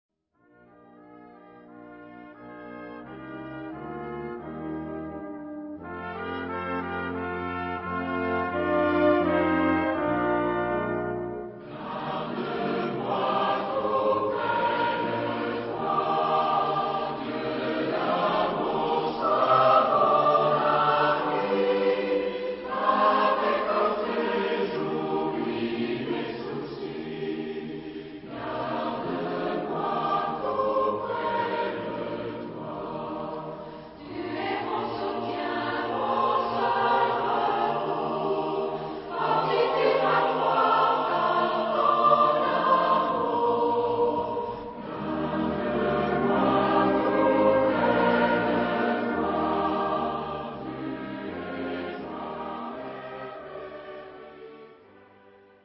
Genre-Style-Forme : Sacré ; Prière
Type de choeur : SATB  (4 voix mixtes )
Instruments : Orgue (1) ad lib